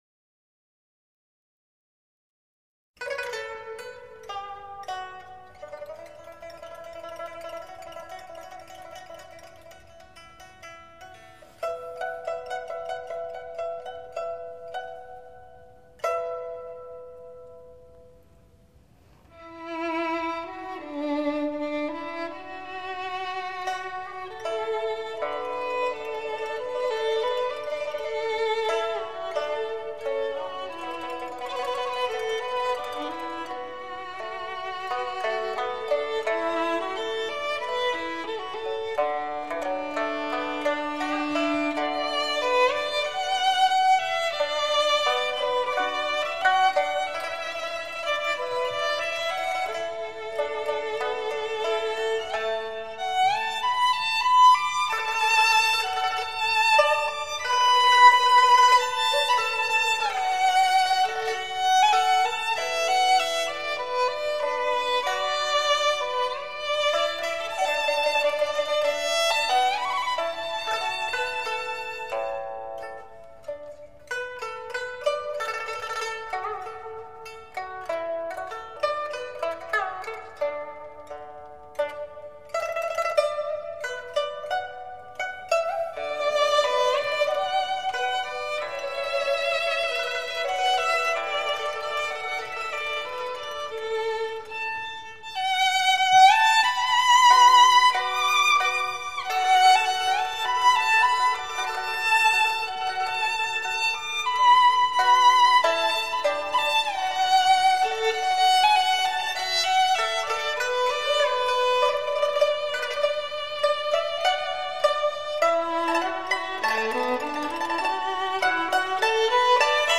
充满灵性的技巧和精致细腻的品味 演奏热情奔放又柔美含蓄 音乐处理具有醉人魅力